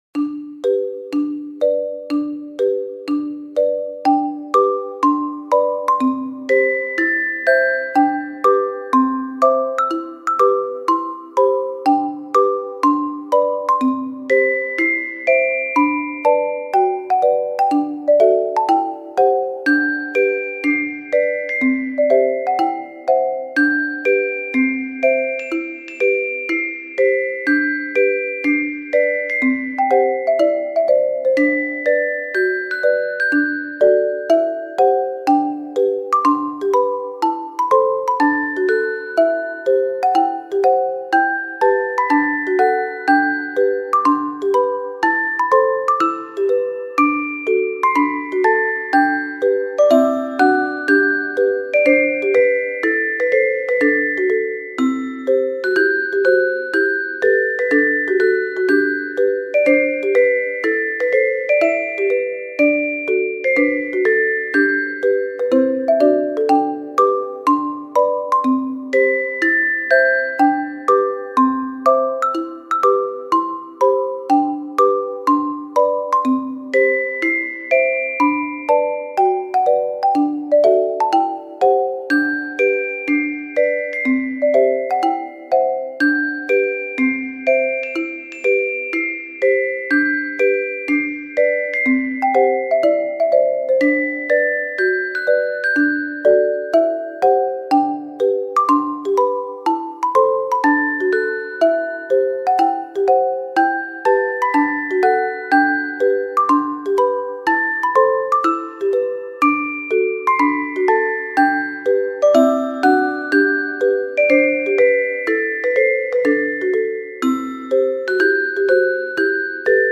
マリンバや鉄琴がポロンポロンと鳴る、可愛い雰囲気のBGMです。